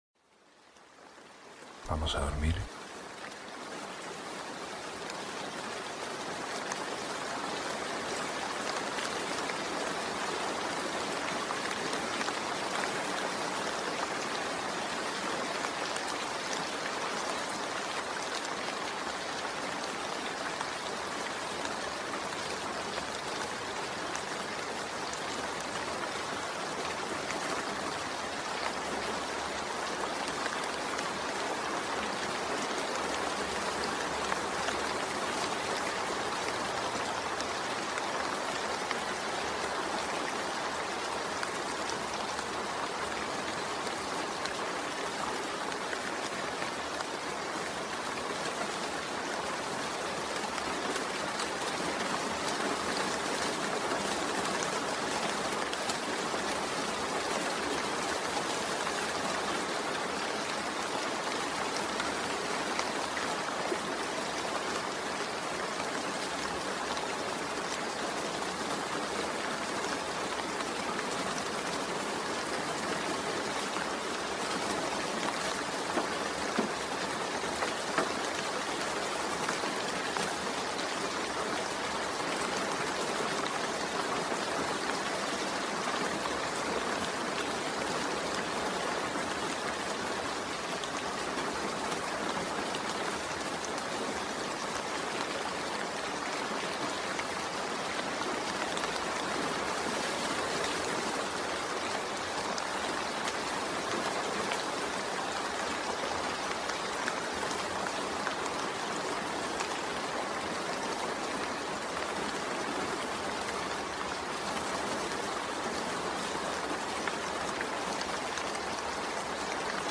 Lluvia relajante para dormir y meditar ✨
Lluvia relajante ✨ Hosted on Acast.